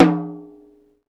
TOM XTOMHI0T.wav